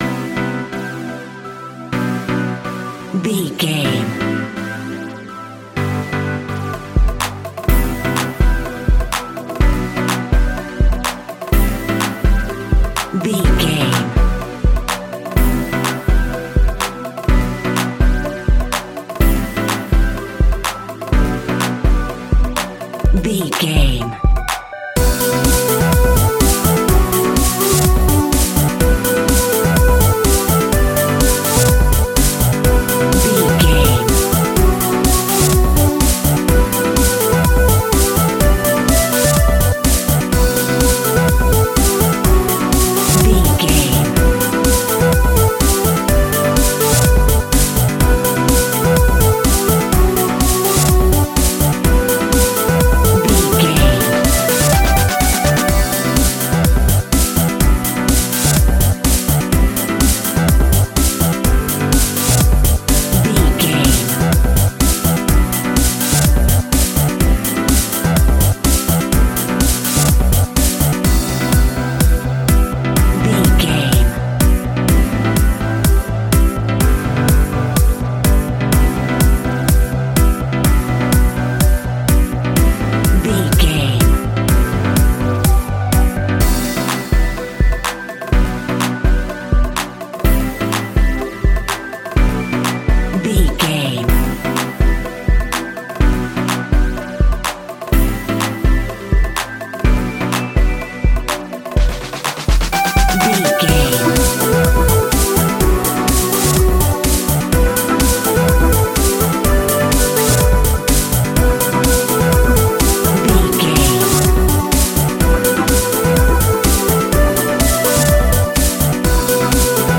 Funky House Dance Music Cue.
Aeolian/Minor
groovy
uplifting
synthesiser
drum machine
deep house
nu disco
upbeat
instrumentals
funky guitar
synth bass